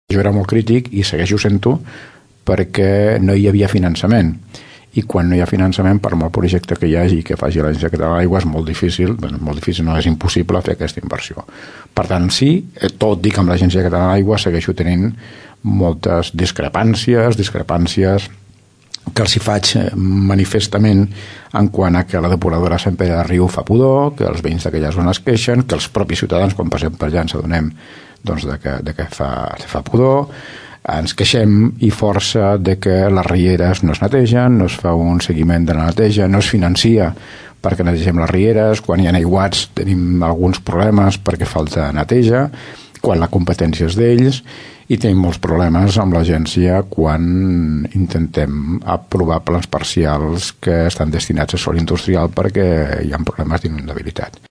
En una entrevista als Serveis Informatius d’aquesta emissora, Joan Carles Garcia també ha anunciat que, l’any vinent, hi haurà la possibilitat de construir una altra depuradora a Tordera.